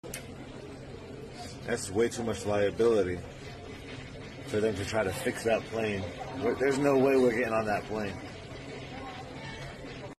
Frontier Airlines Airbus A320 251N aircraft sound effects free download
Frontier Airlines Airbus A320-251N aircraft (N365FR) left engine ingested a ground air-conditioning hose at Charlotte Douglas International Airport